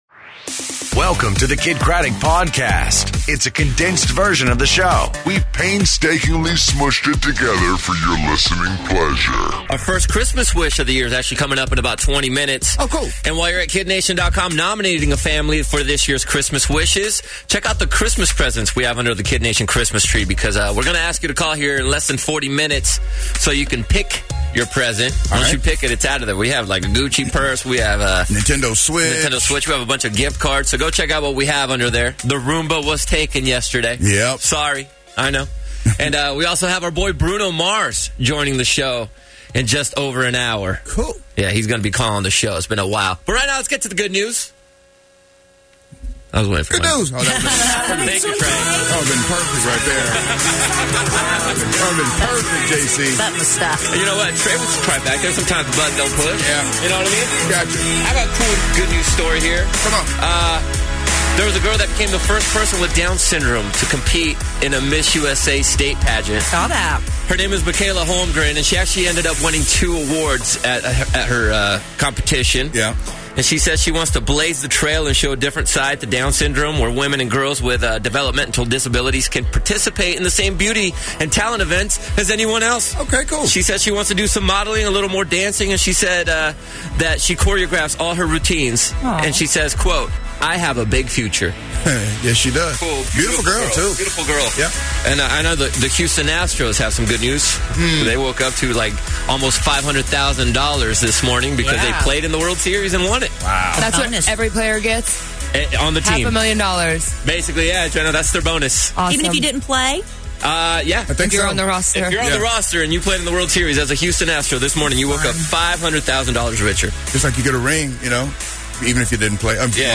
Bruno Mars Calls The Show, Our First Christmas Wish Of the Season, And Good News Tuesday